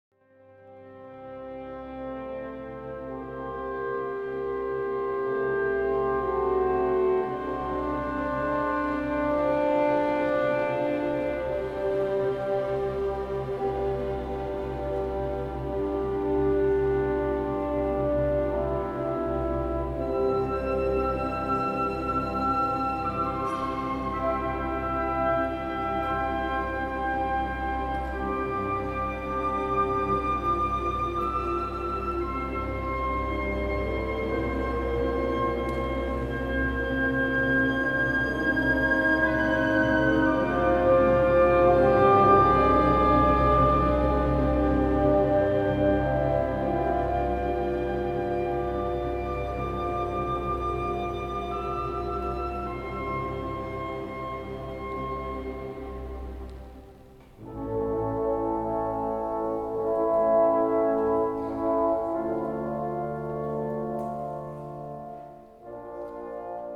1981/6 第６回サマーコンサート
交響曲第１番ハ短調（ブラームス）   管弦楽 石川厚生年金会館
ソロ部分